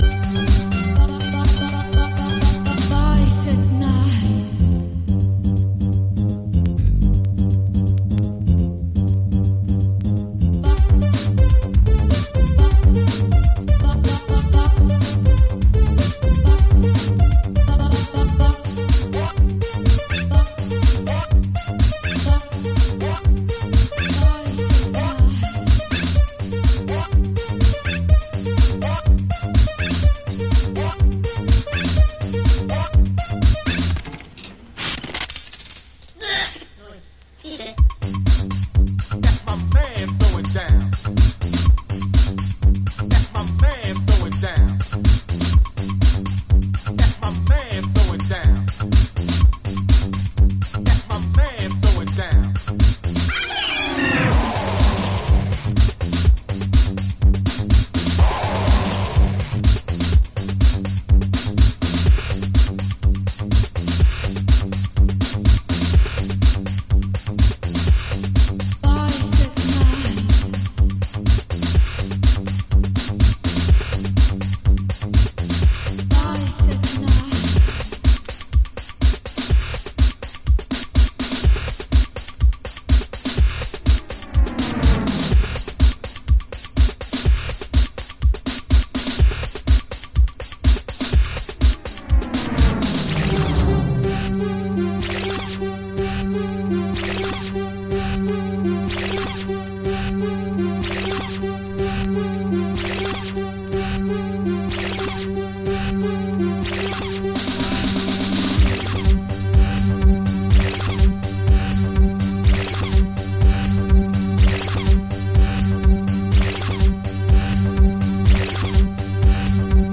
好事家にはたまらないブリープやレイヴともシンクロするアーリーイタロアシッド。
Early House / 90's Techno